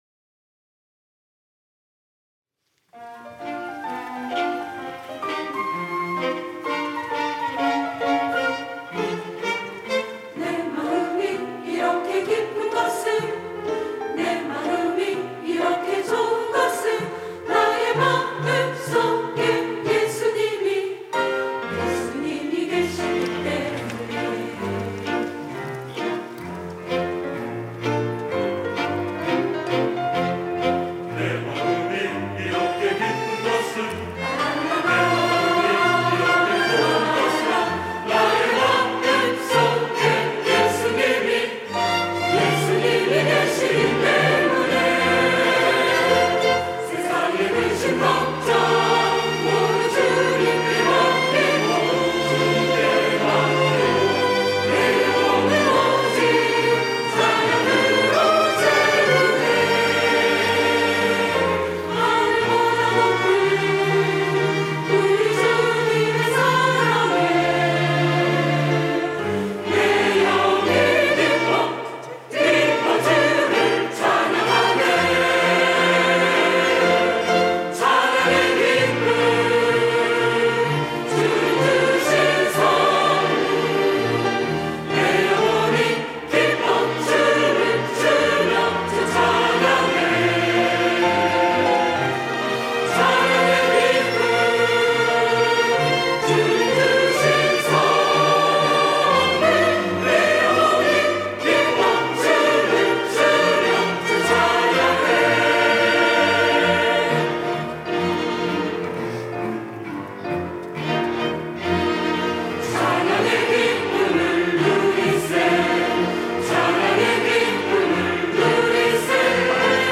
호산나(주일3부) - 찬양의 기쁨
찬양대